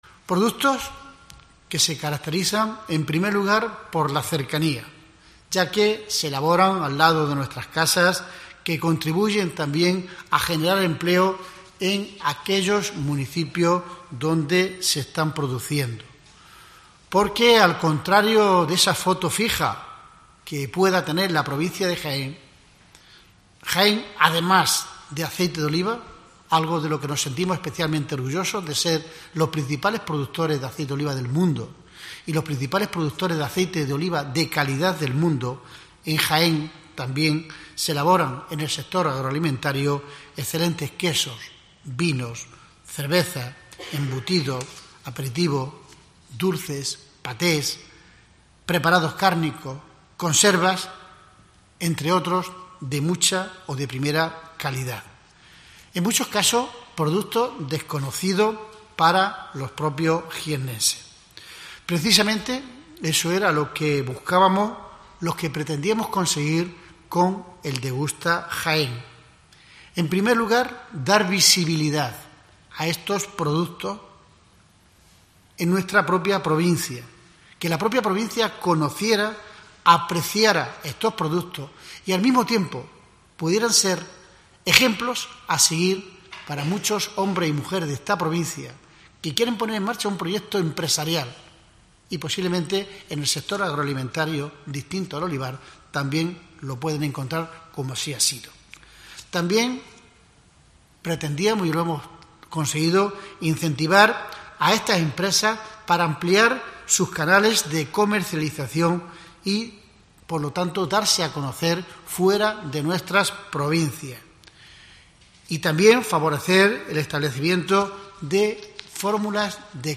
Francisco Reyes da detalles de este 10 aniversario